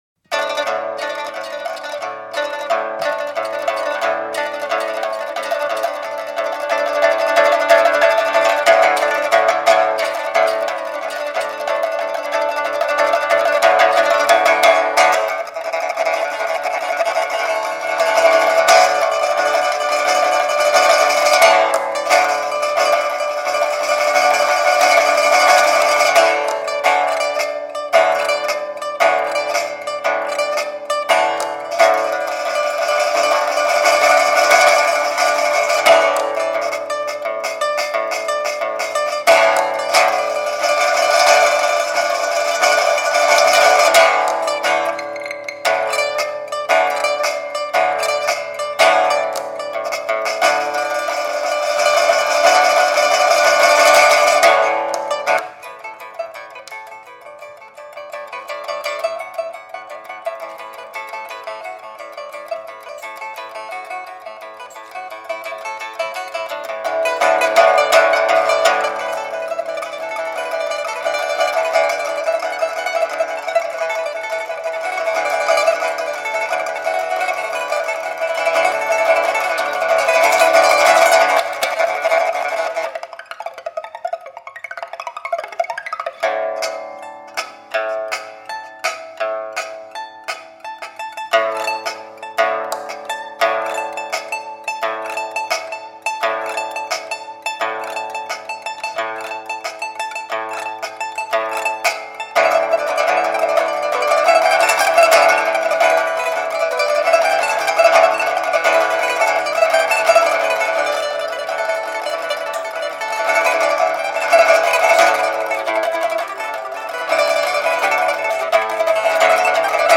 Жанр: Chinese Traditional, Pipa & Guzheng, World